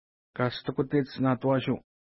Pronunciation: ka:stukutets na:twa:ʃu:
Pronunciation